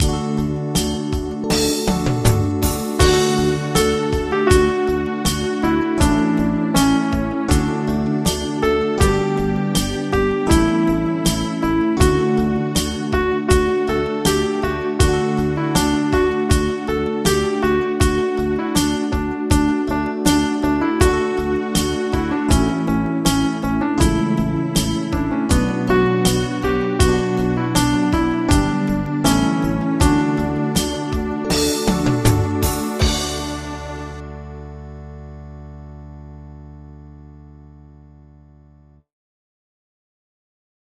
Audio Midi Bè Alto: download